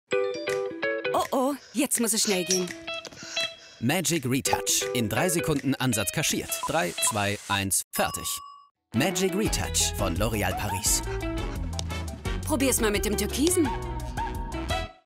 Jung, dynamisch, cool & souverän!
Sprechprobe: Werbung (Muttersprache):
young german voice over artist